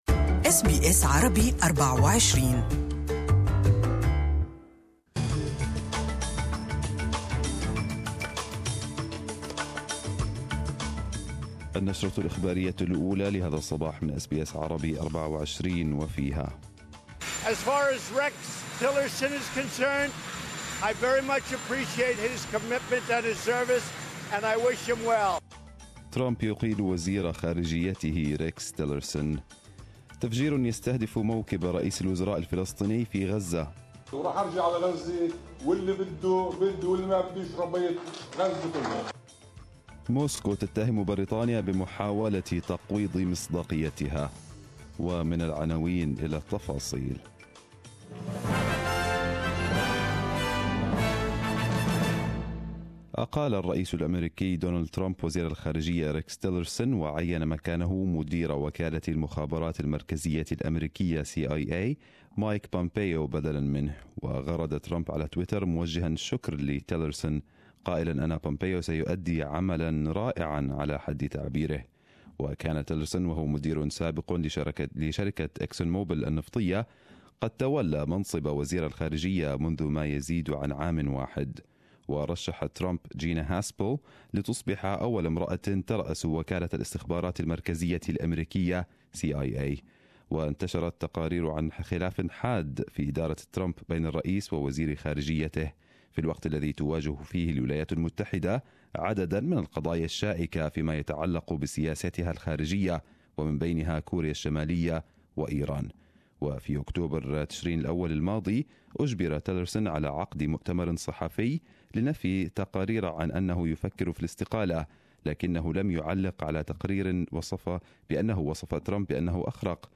Arabic News Bulletin 14/03/2018